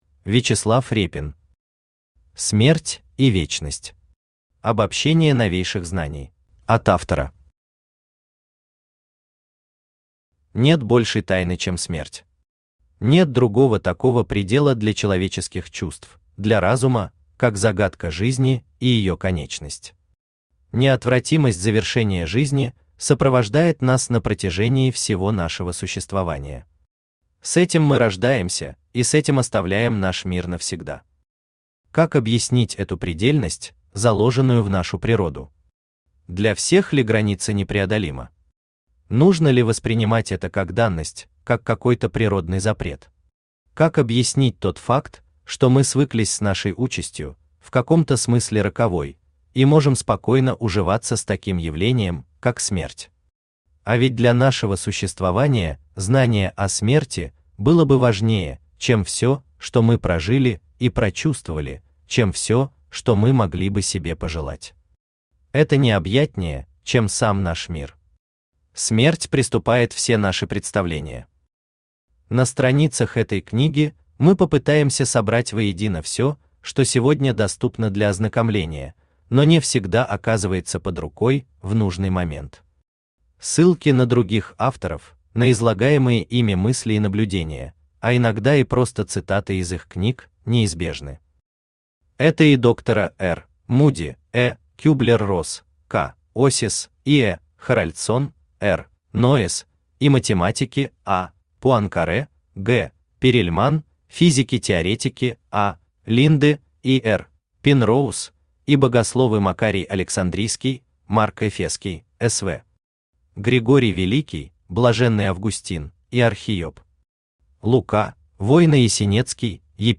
Аудиокнига Смерть и вечность. Обобщение новейших знаний | Библиотека аудиокниг
Обобщение новейших знаний Автор Вячеслав Борисович Репин Читает аудиокнигу Авточтец ЛитРес.